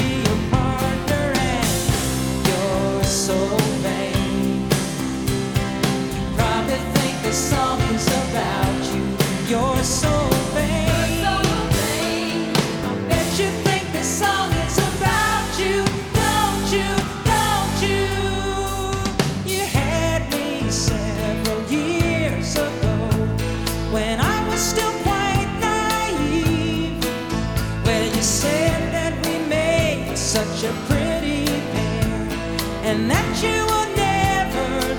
# Singer